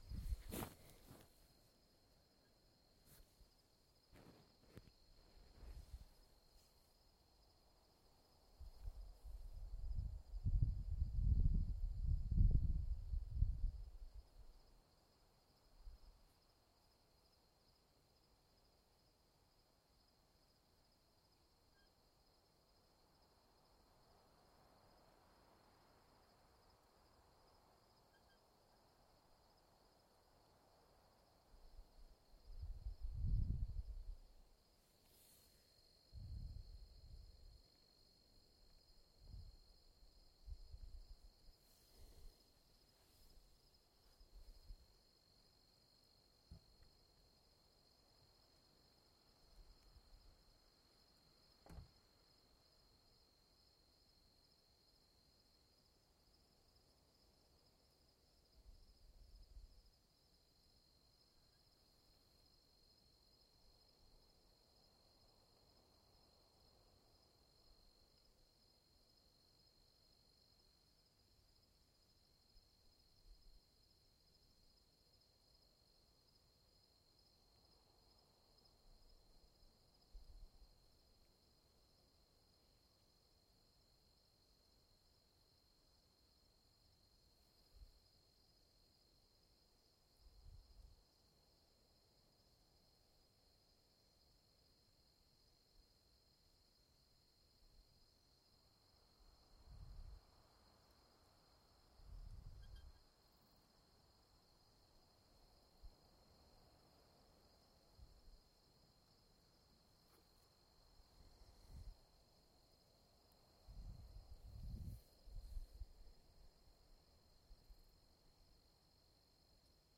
氛围 夜晚的蟋蟀
描述：在田野蟋蟀声音轻微植物沙沙作响。
Tag: 动物 晚上 蟋蟀 臭虫 氛围 昆虫 性质 现场录音 OWI 晚上 板球